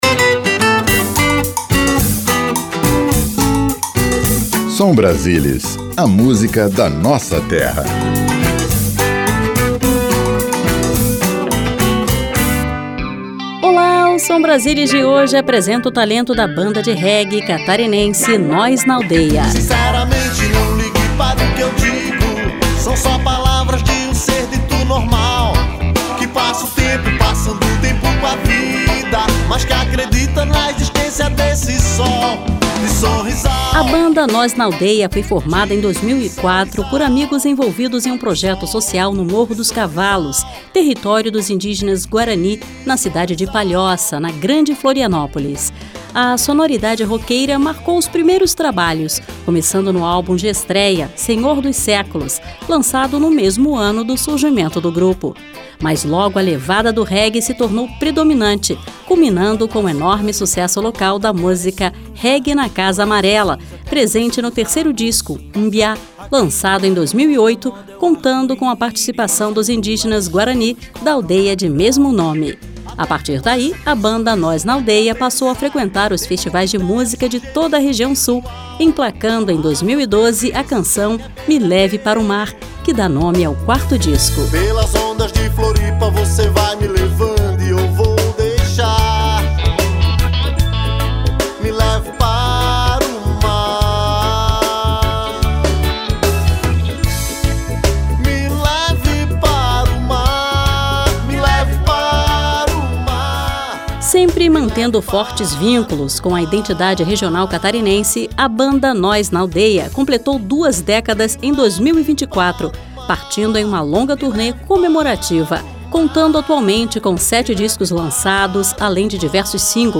A banda catarinense Nós Naldeia foi formada em 2004 por um grupo de amigos envolvidos em um projeto social na aldeia guarani da cidade de Palhoça, na grande Florianópolis. Partindo de uma sonoridade roqueira no início, a banda se firmou com a levada do reggae, lançando em 2008 seu maior sucesso, a música "Reggae na Casa Amarela", presente no disco Mbyá, que também contou com a participação dos indígenas da aldeia de mesmo nome.